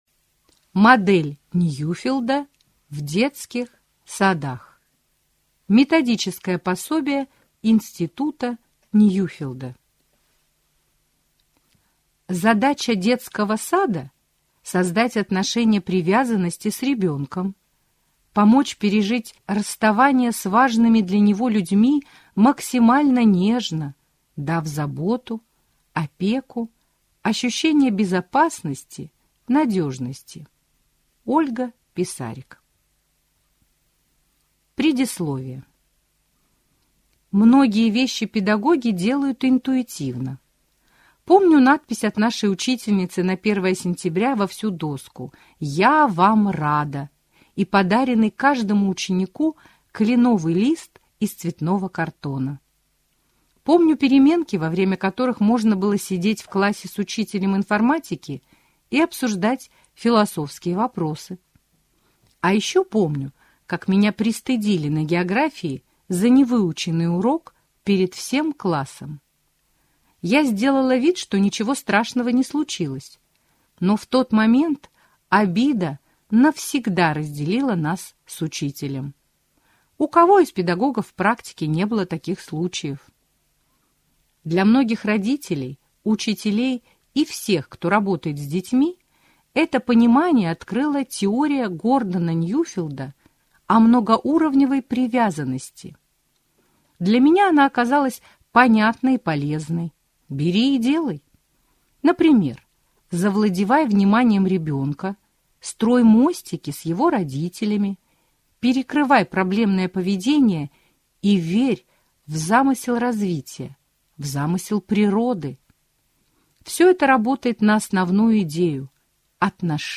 Аудиокнига Модель Ньюфелда в детских садах | Библиотека аудиокниг